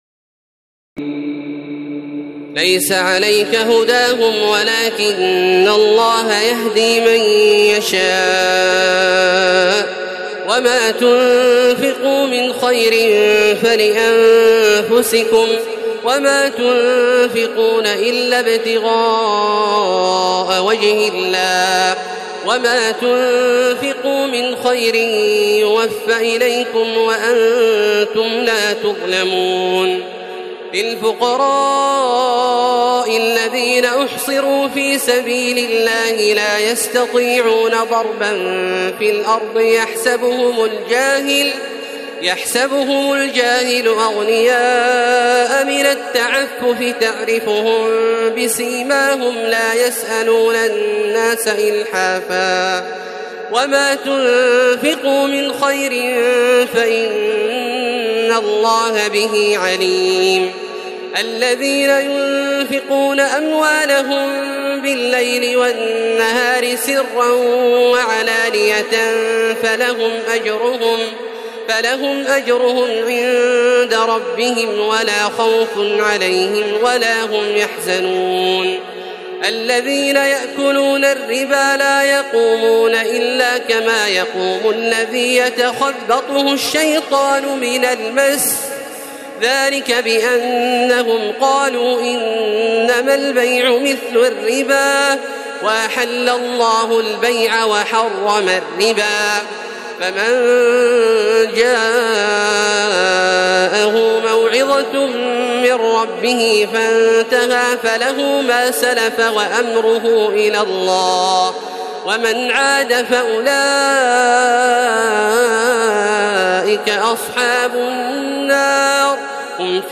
تراويح الليلة الثالثة رمضان 1432هـ من سورتي البقرة (272-286) و آل عمران (1-63) Taraweeh 3st night Ramadan 1432H from Surah Al-Baqara and Surah Aal-i-Imraan > تراويح الحرم المكي عام 1432 🕋 > التراويح - تلاوات الحرمين